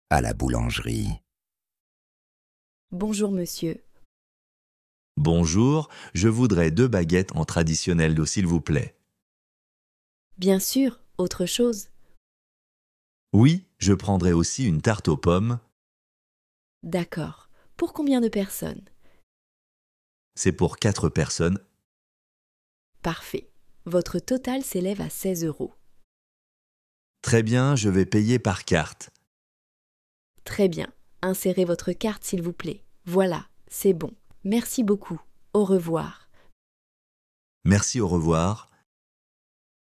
Dialogue FLE